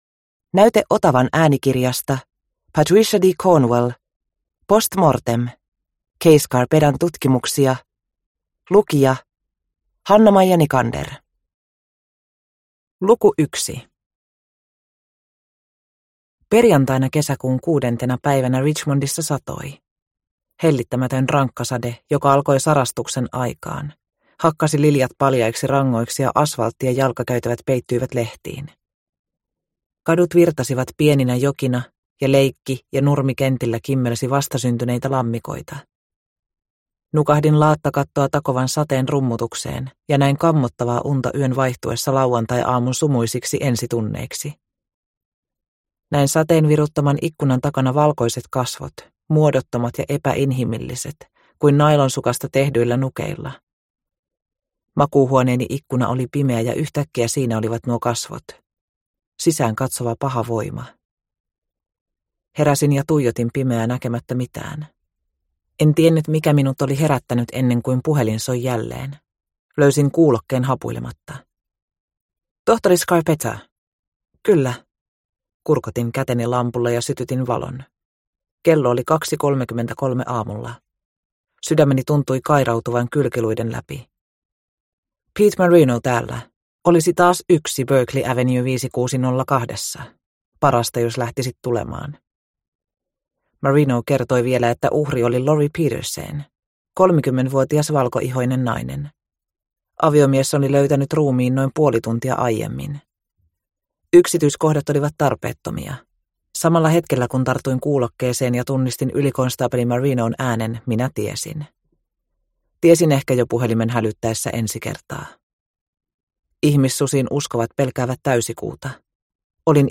Post mortem – Ljudbok – Laddas ner